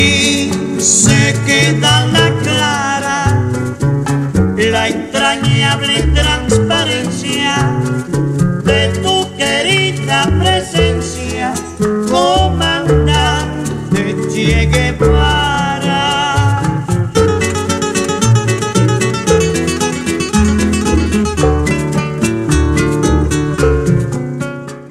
Ce dernier a plus composé des Sons, (salsas lentes).